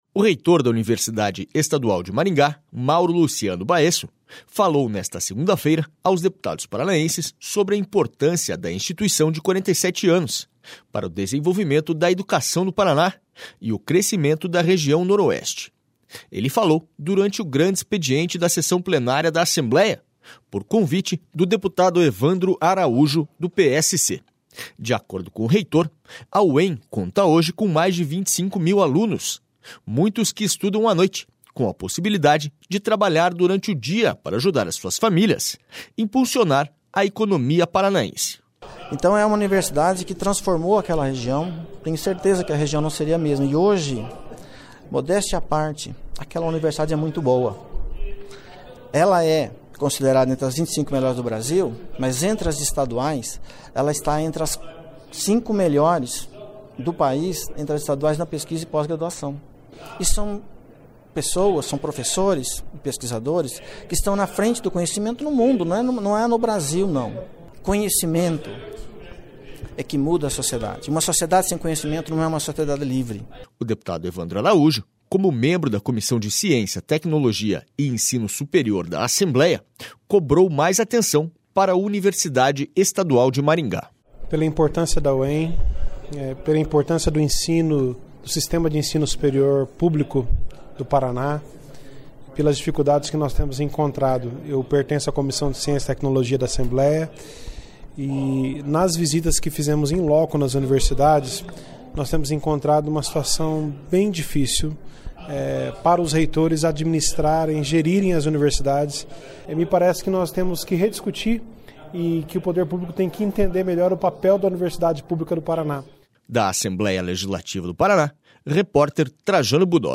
Ele falou durante o grande expediente da sessão plenária por convite do deputado Evandro Araújo, do PSC.//